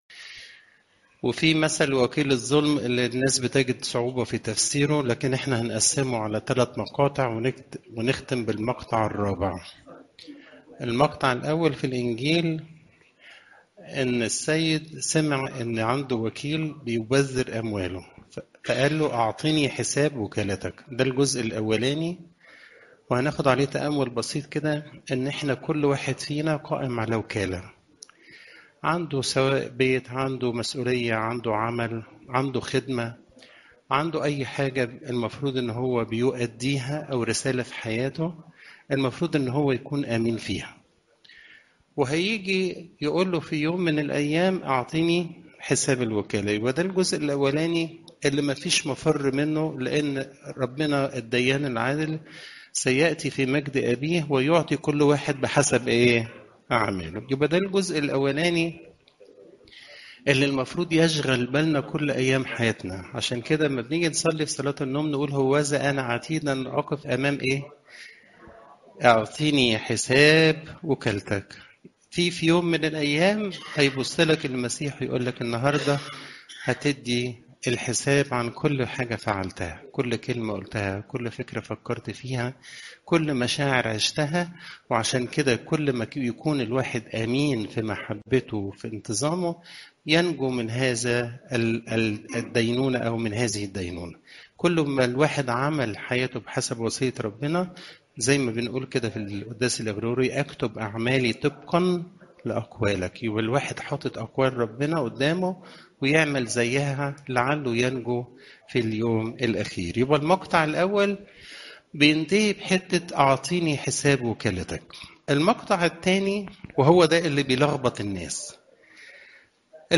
عظات قداسات الكنيسة (لو 16 : 1 - 12)